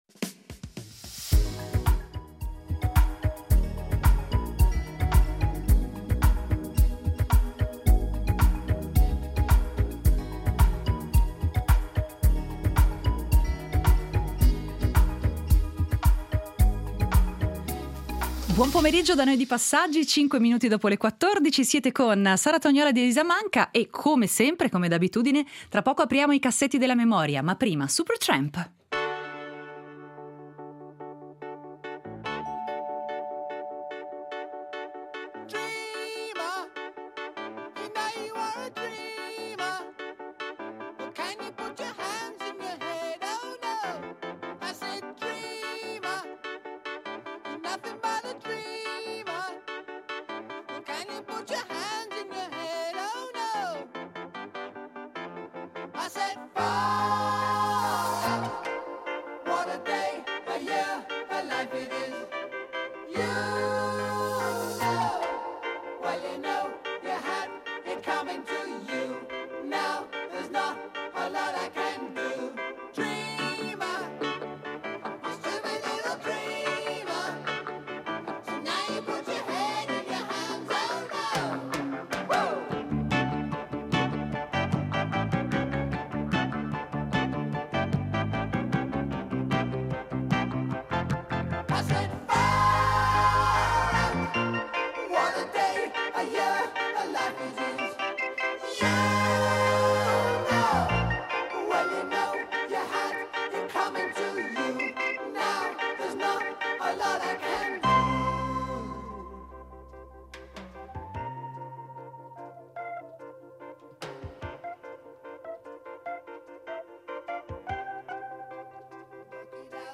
In Tracce, estratti d’archivio sulla tragicommedia La Stria , scritta 150 anni fa da Giovanni Andrea Maurizio di Vicosoprano. Ci sarà anche un frammento d’archivio su Magic Johnson, una delle più grandi leggende del basket americano e internazionale, e su Albert Camus, scrittore francese, autore di Lo Straniero .